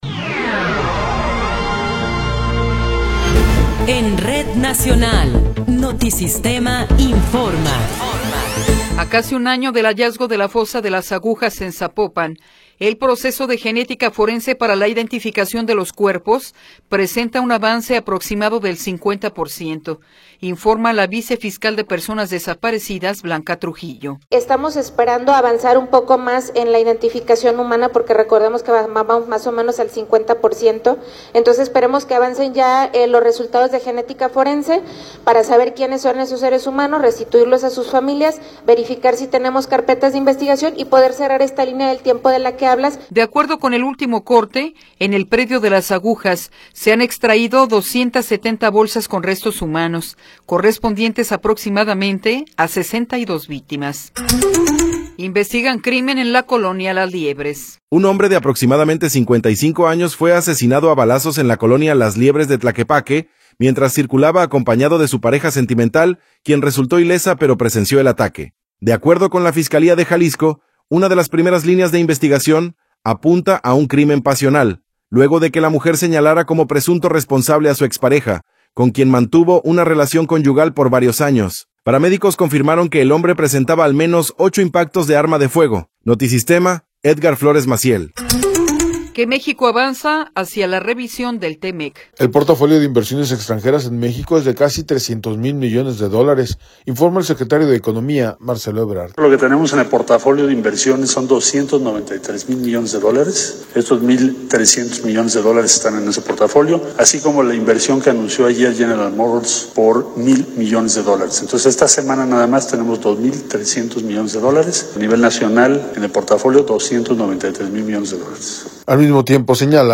Noticiero 11 hrs. – 15 de Enero de 2026
Resumen informativo Notisistema, la mejor y más completa información cada hora en la hora.